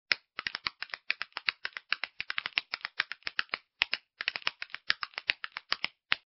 Звук деревянных ложек